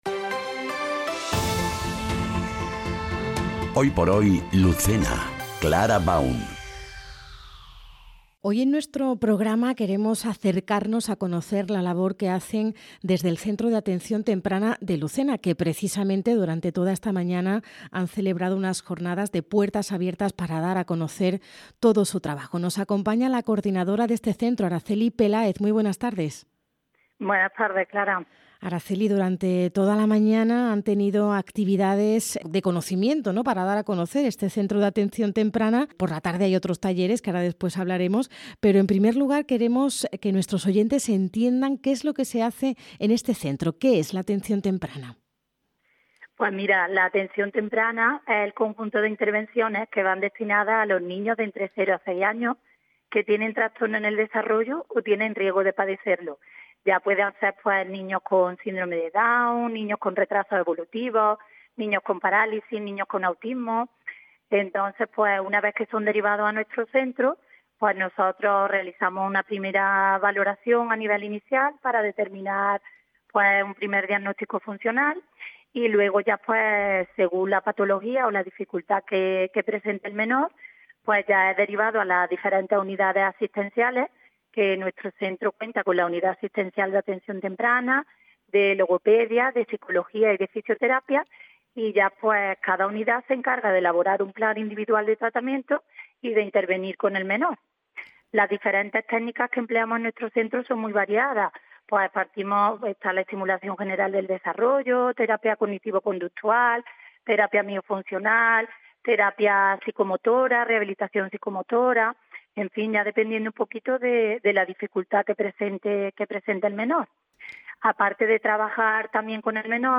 Entrevista | Centro de Atención Temprana de Lucena - Andalucía Centro